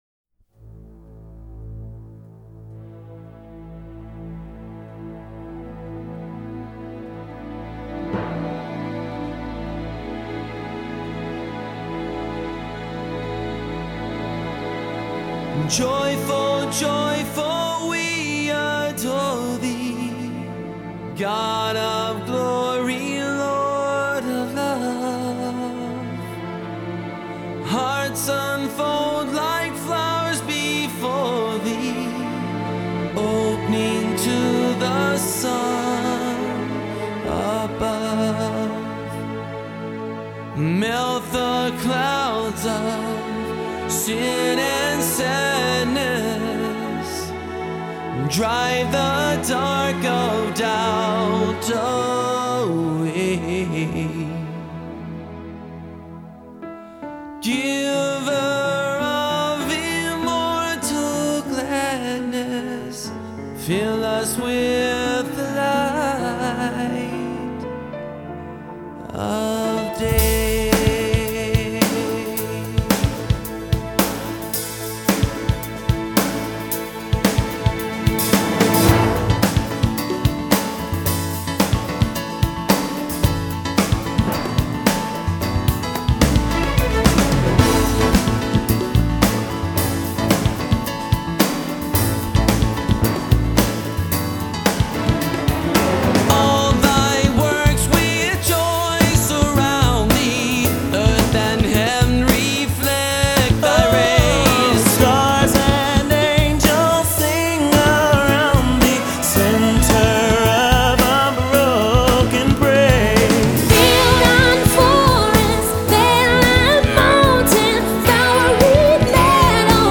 --英文现代摇滚版!